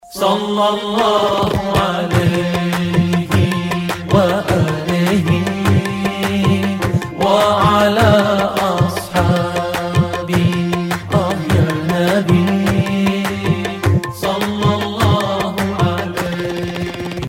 زنگ موبایل(با کلام) ملایم و زیبای